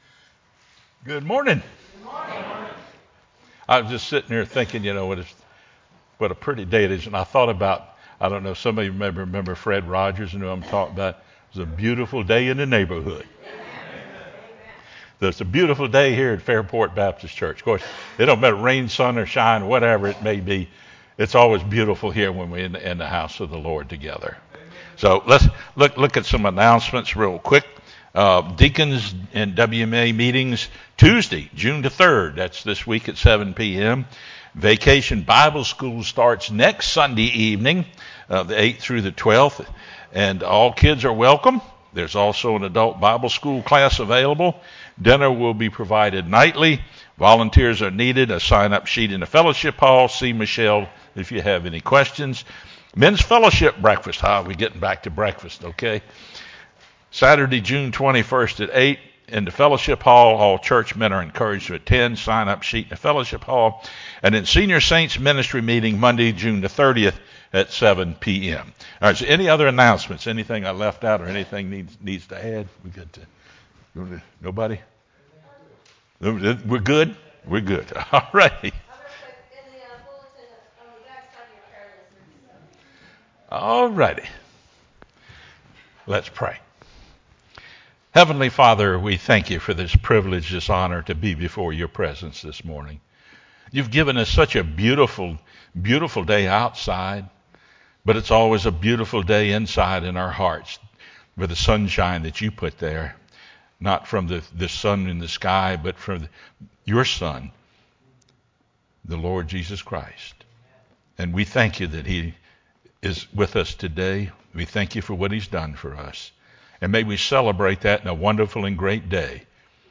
sermonJun1-CD.mp3